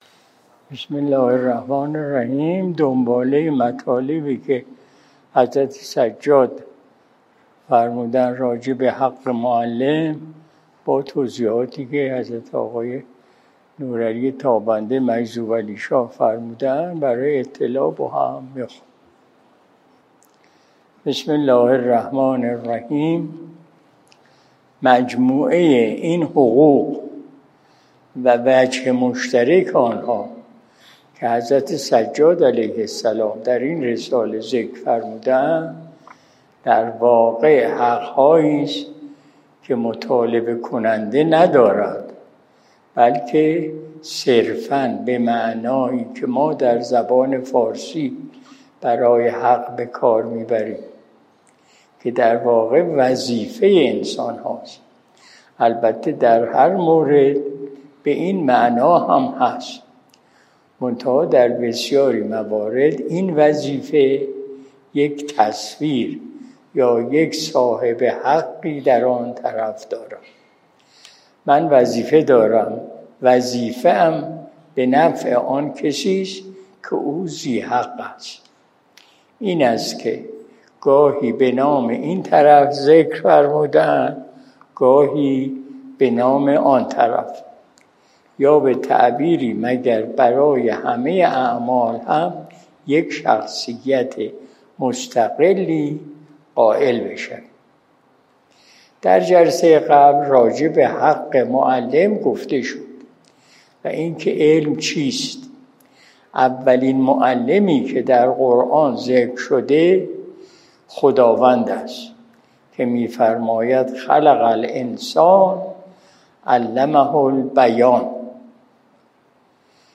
مجلس شب جمعه